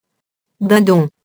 dindon [dɛ̃dɔ̃]